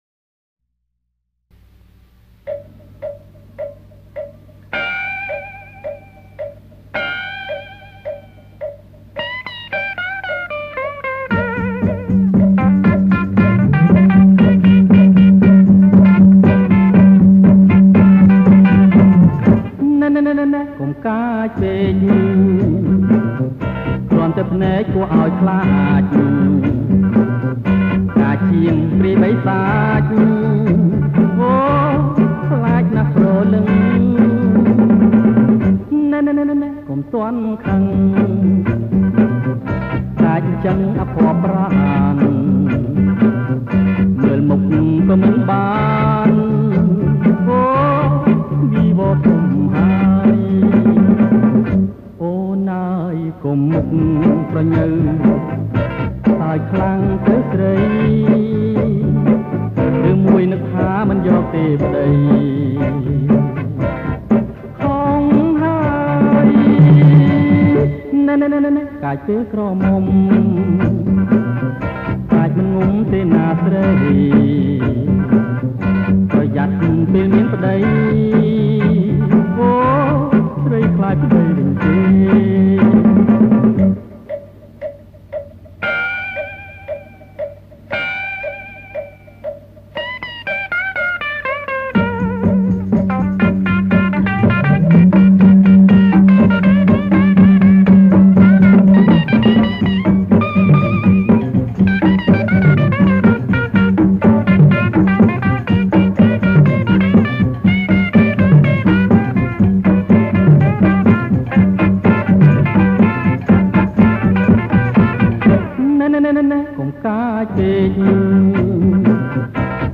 • ប្រគំជាចង្វាក់ Jerk Lent